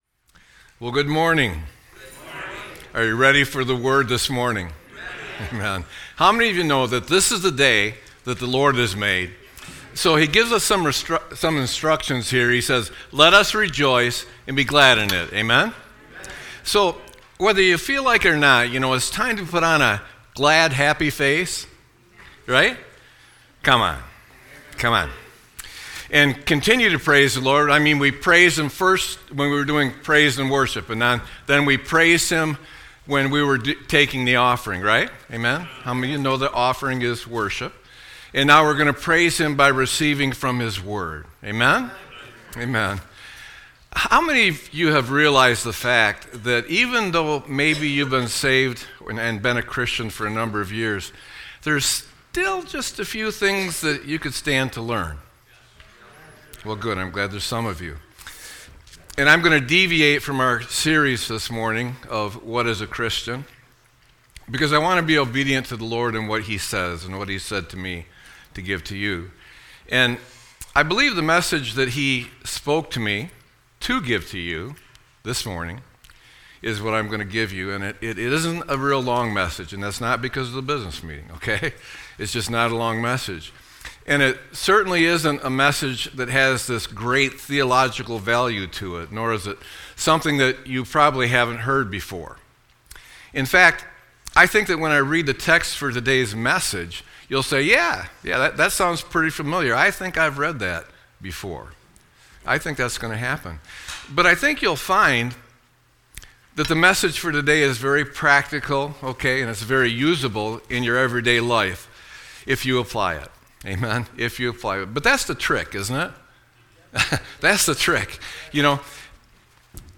Sermon-5-04-25.mp3